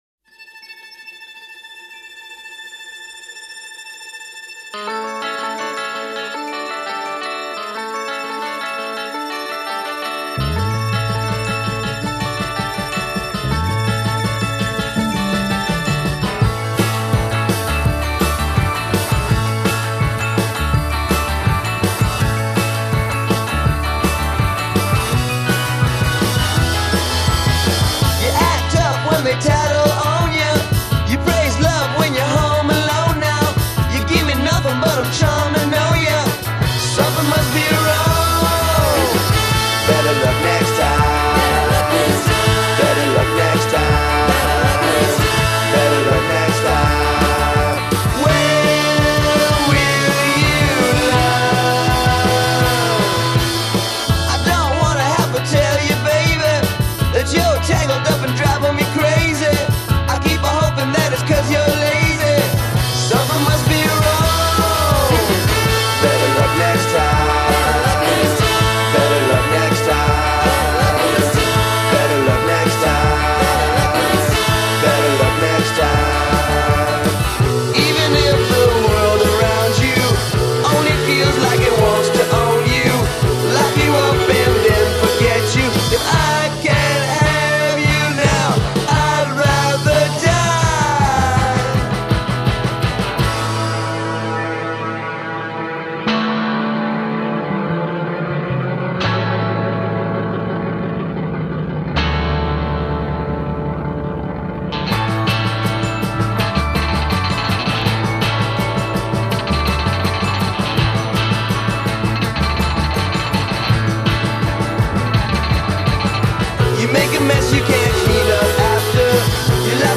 con la sua miscela iper-energetica di funk e garage.